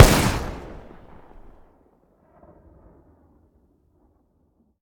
weap_mike203_fire_plr_atmos_03.ogg